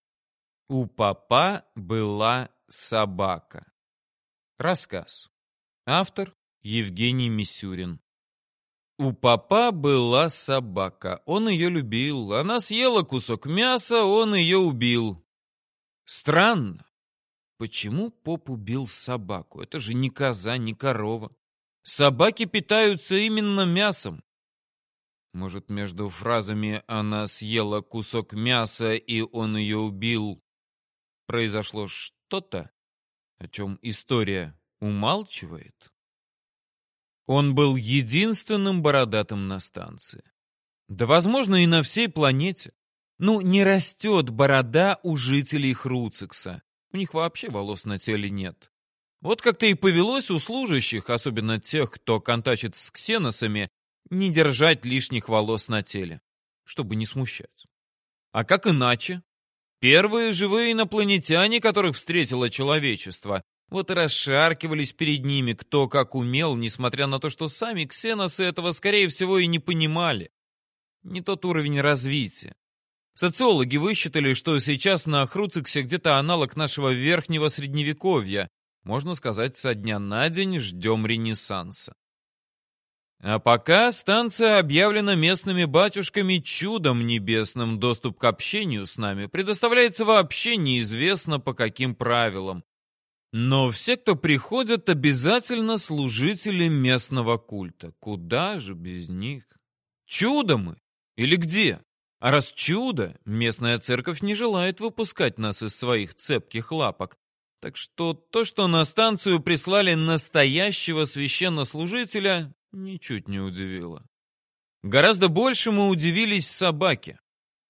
Аудиокнига У попа была собака | Библиотека аудиокниг